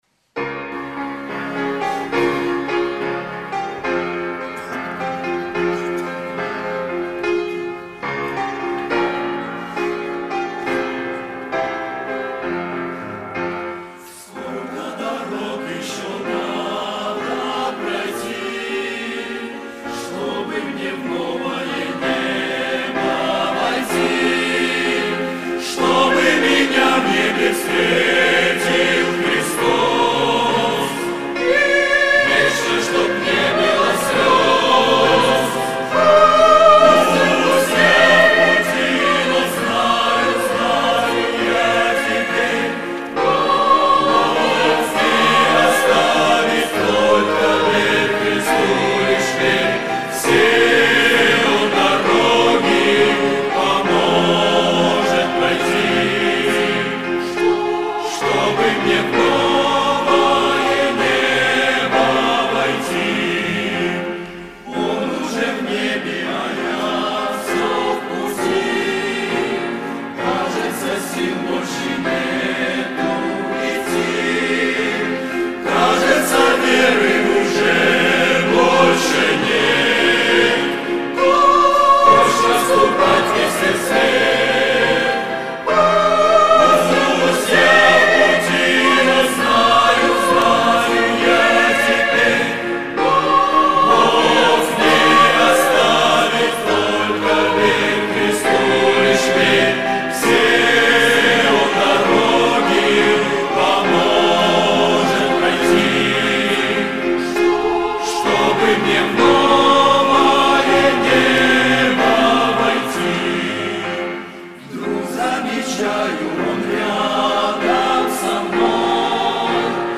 Богослужение 27.10.2024
Сколько дорог еще пройти? - Хор (Пение)[